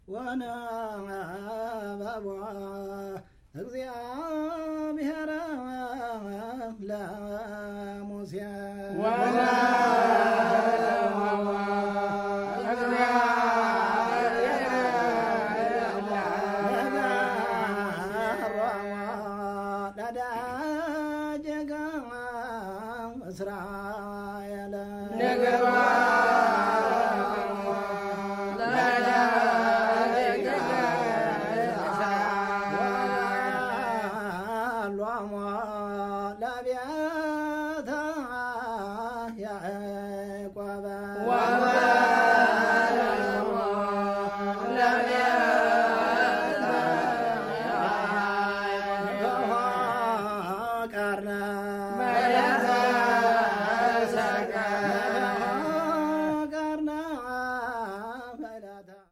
The Ethiopian liturgy is composed of spoken and sung prayers, exclusively performed by men, in Gé’ez language.
The atmosphere that comes out reflects of an African originality.